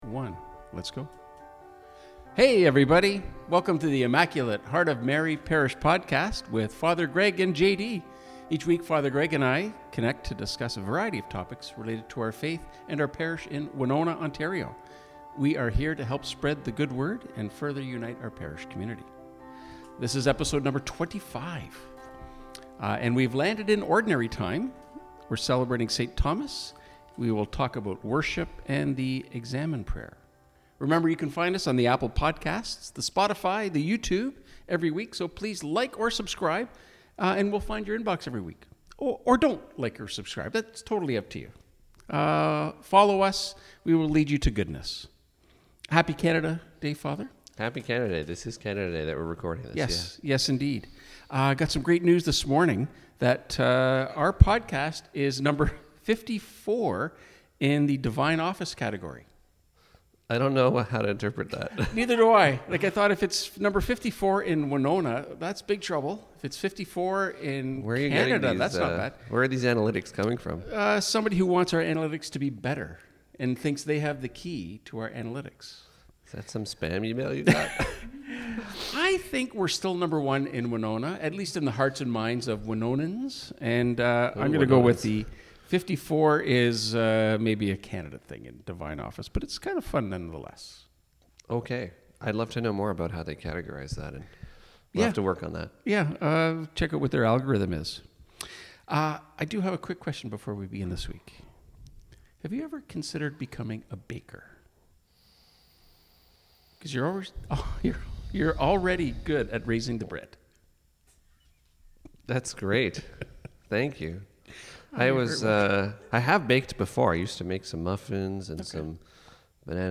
We are here to help spread the good news and help to further unite our parish community.